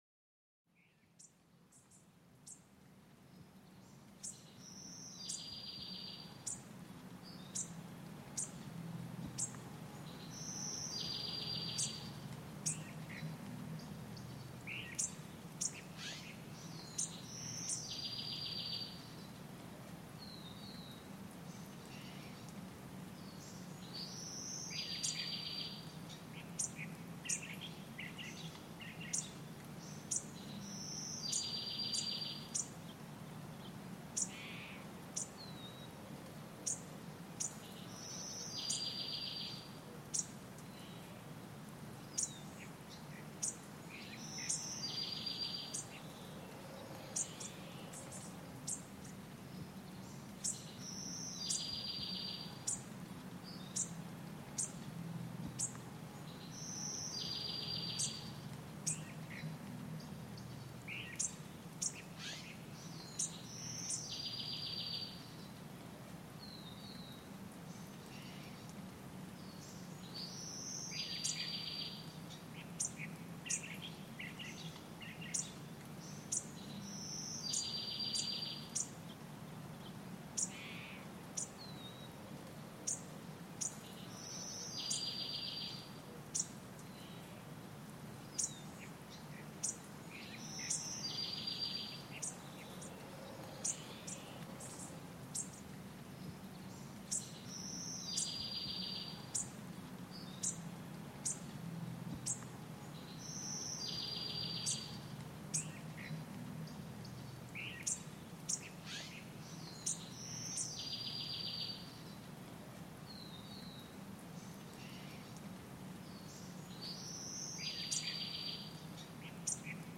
Escucha de Aves en el Bosque: Serenidad y Calma a Través de sus Cantos
Adéntrate en un bosque tranquilo donde los diversos cantos de aves te envuelven en una calma absoluta. Experimenta los beneficios relajantes y rejuvenecedores de estas melodías naturales.